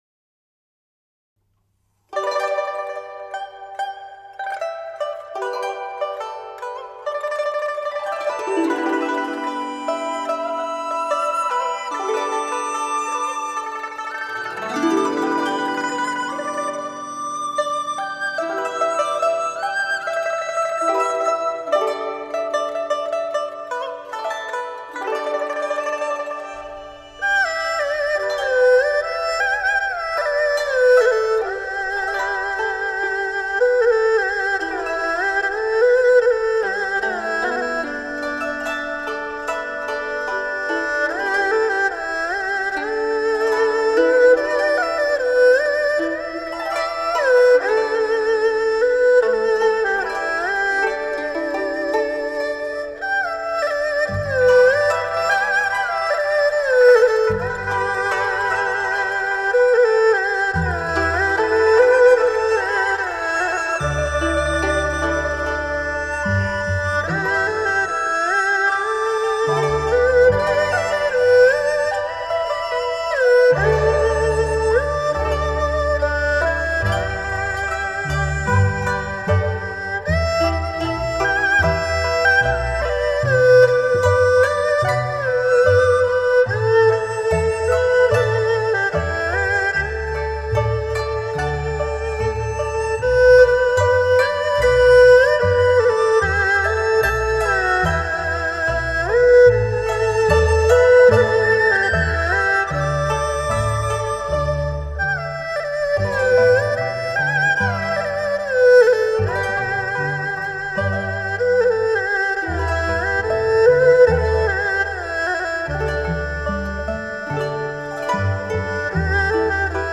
古筝
琵琶
笛子
大提琴
乐曲极具生气，生猛而灵巧， 音韵悠扬丰富，充满美态， 录音清晰通透，细致无暇， 叫人一听难忘……